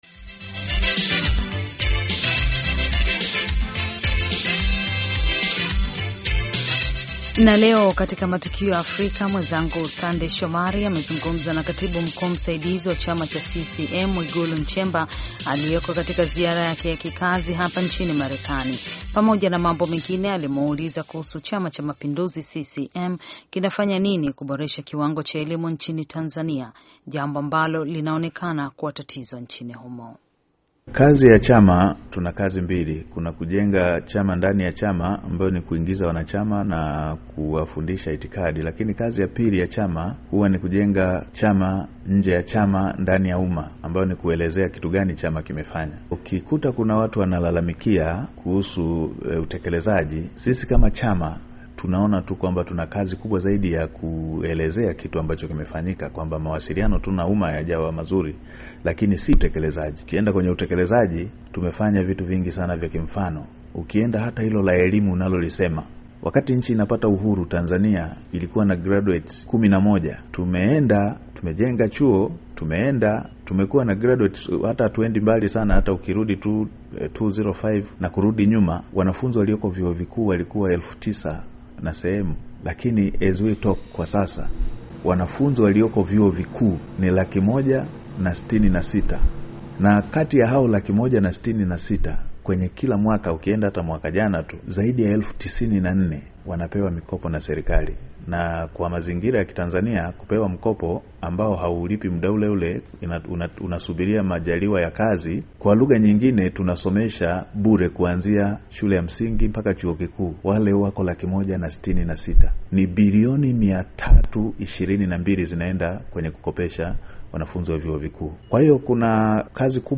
Matukio Afrika:Tanzania/CCM - mahojiano katibu mkuu Mwigulu Nchemba - 5:43